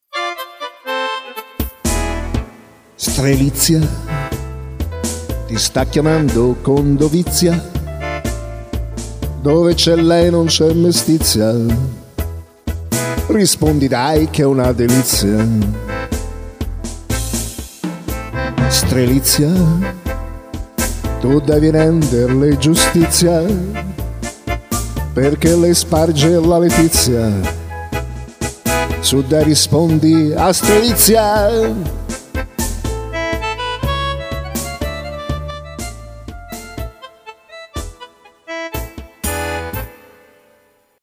Una suoneria personalizzata